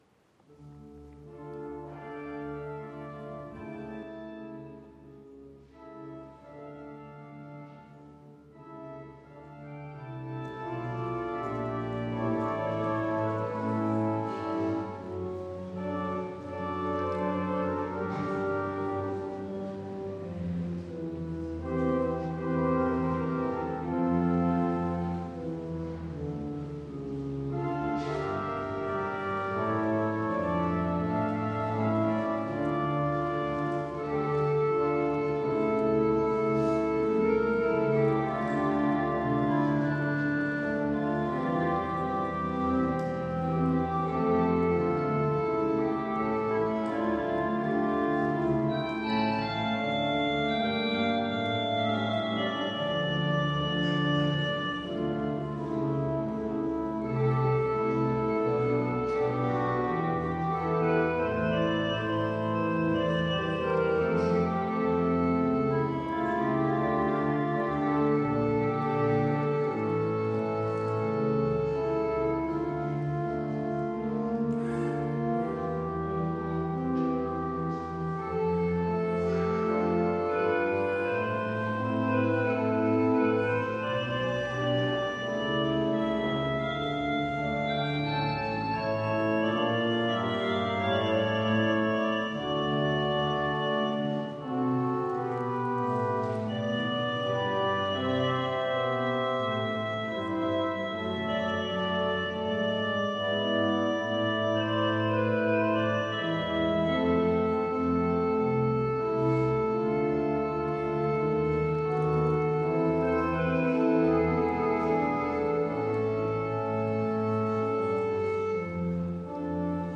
Orgelstück zum Ausgang
Audiomitschnitt unseres Gottesdienstes vom Sonntag Lätare 2024